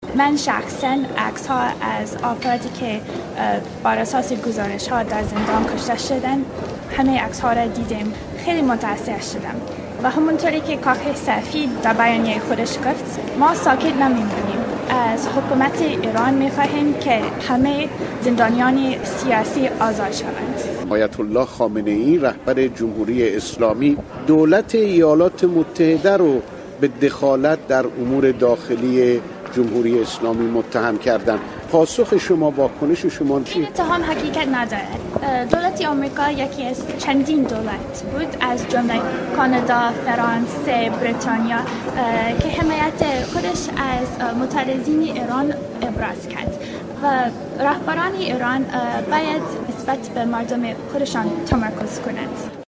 یک مقام وزارت خارجه آمریکا در یک گفتگو با رادیو فردا تاکید کرد که ایالات متحده در مورد نقض حقوق بشر حکومت ایران ساکت نخواهد نشست.